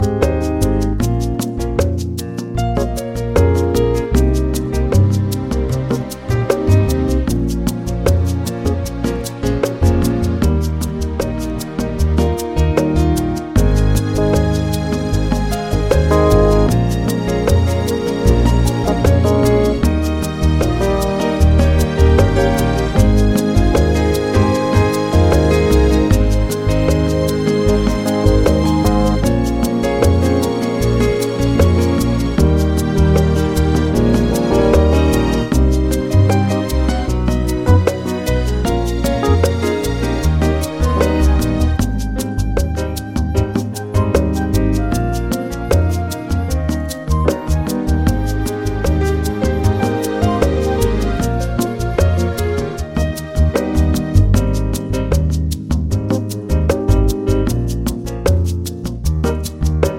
Lower Male Key of Ab